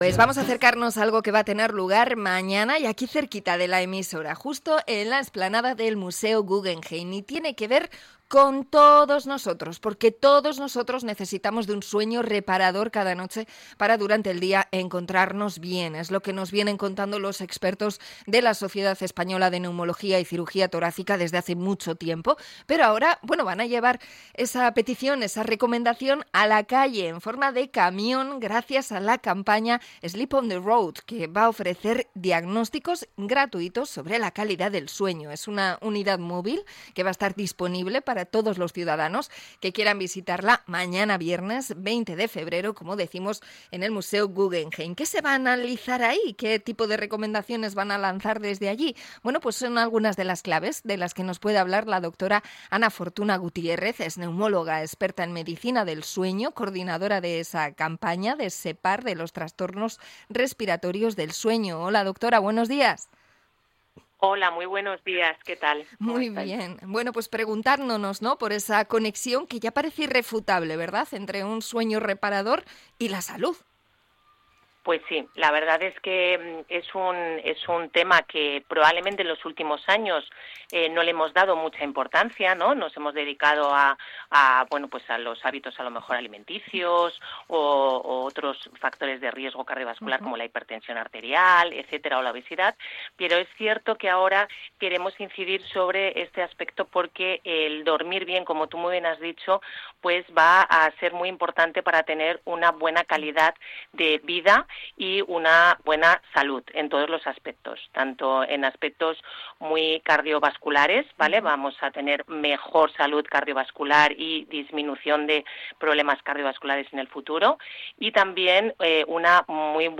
Entrevista a SEPAR por la calidad del sueño y la salud